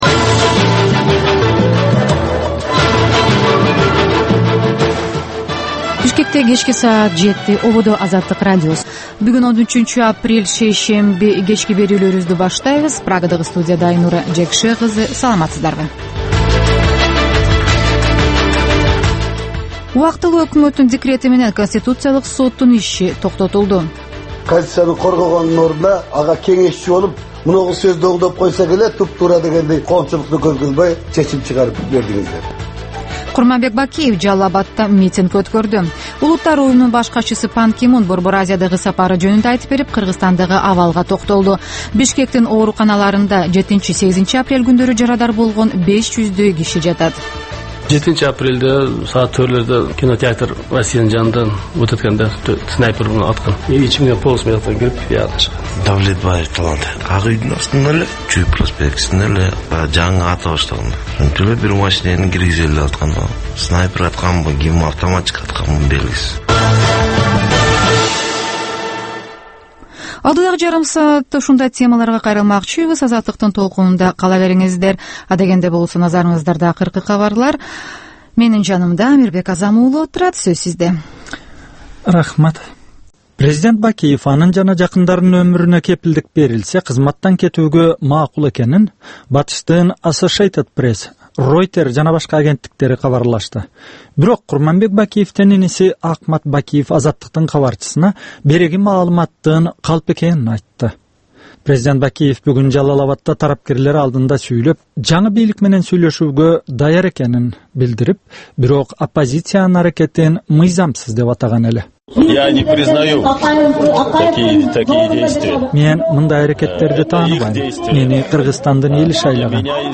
"Азаттык үналгысынын" бул кечки алгачкы берүүсү (кайталоо) жергиликтүү жана эл аралык кабарлардан, репортаж, маек, баян жана башка берүүлөрдөн турат.